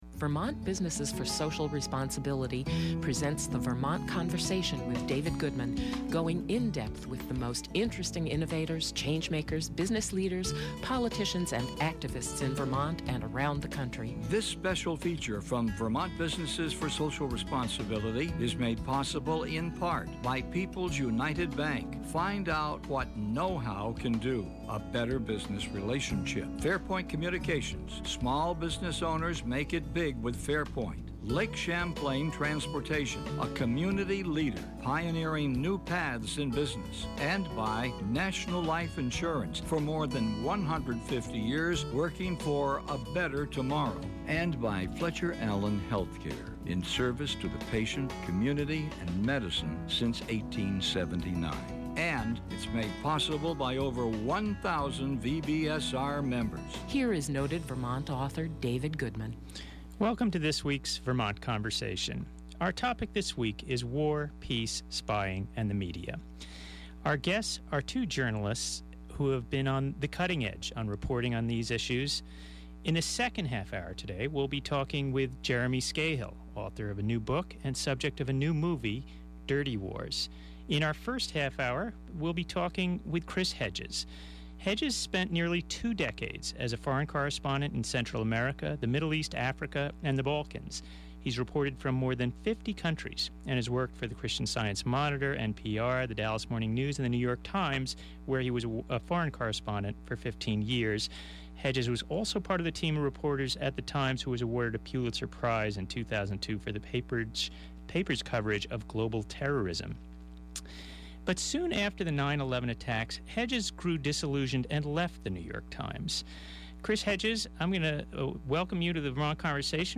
Dirty wars, peace, & the media: A conversation with two of America's top muckraking journalists: former NY Times reporter and Pulitzer Prize winner Chris Hedges, and Jeremy Scahill, bestselling author of Dirty Wars and national security correspondent of The Nation.